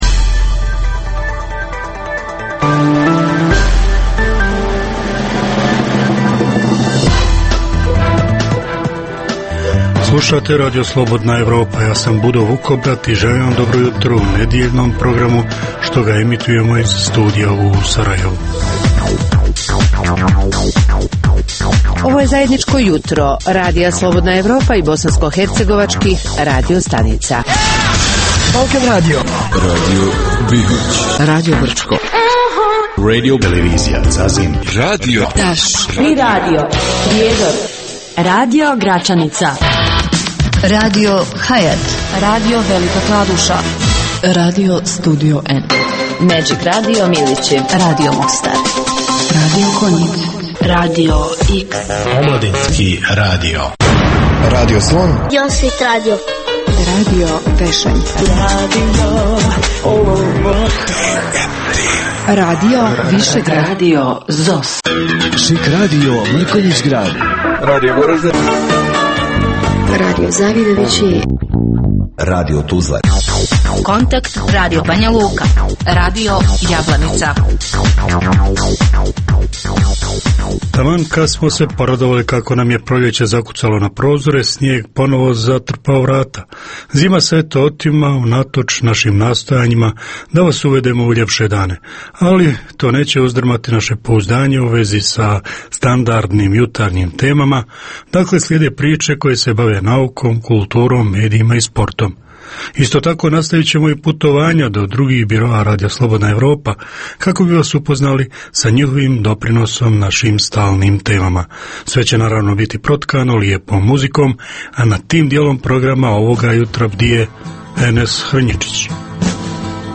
Jutarnji program namijenjen slušaocima u Bosni i Hercegovini. Kao i obično, uz vijesti i muziku, poslušajte pregled novosti iz nauke i tehnike, te čujte šta su nam pripremili novinari RSE iz Zagreba i Beograda.